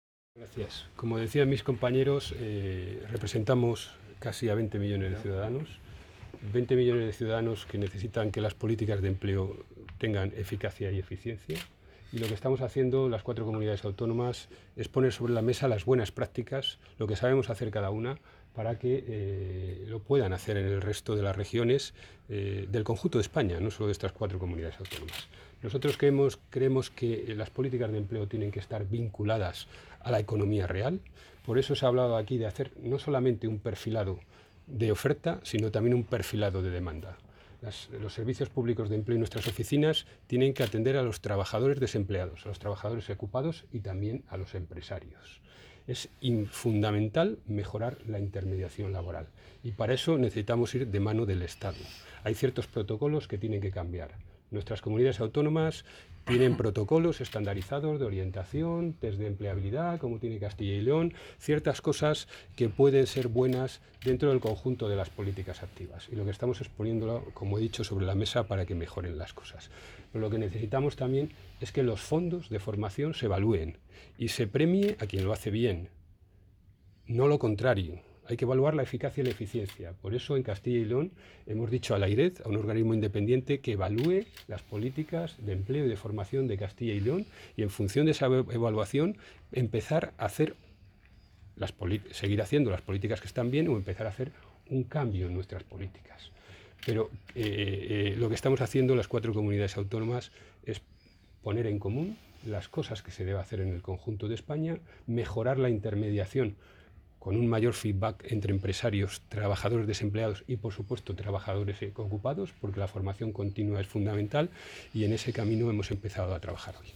Declaraciones del consejero tras la reunión.